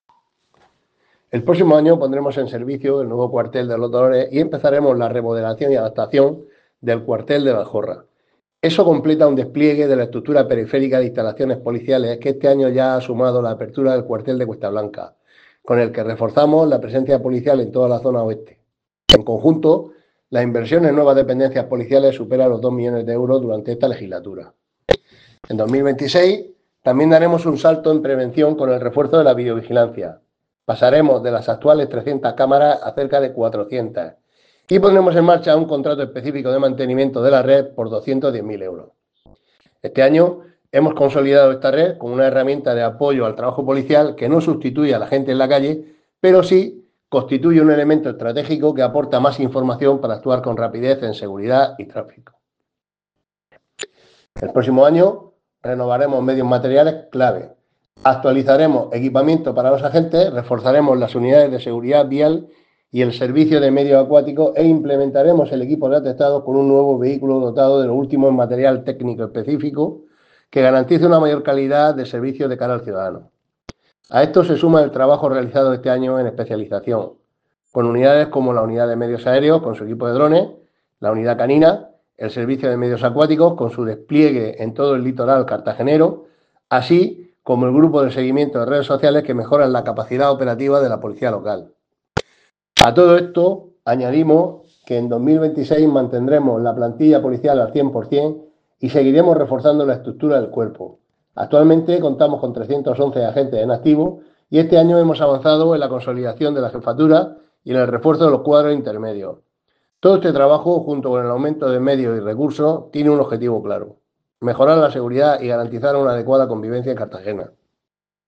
Enlace a Declaraciones de José Ramón Llorca sobre inversión en materia de seguridad